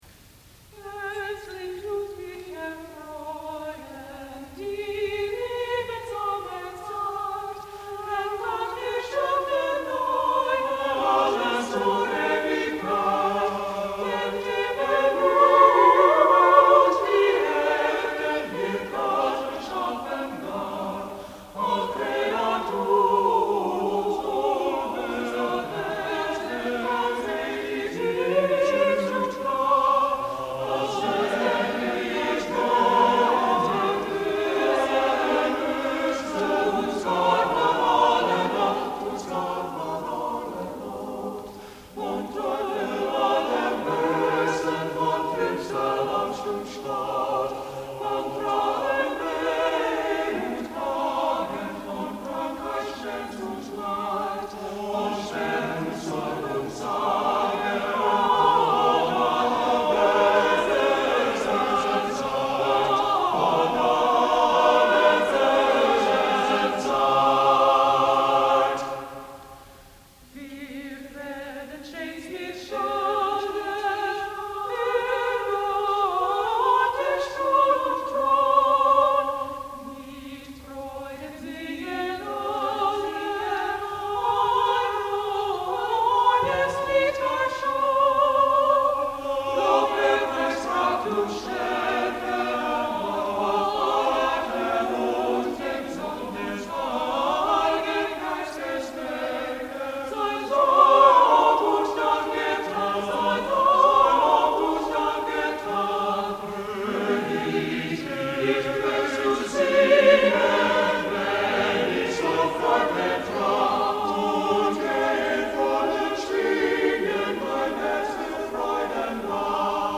This lovely setting of a well-known text by Johann Walter is masterfully through-composed . . . a near ecstatic jubilation of the redeemed.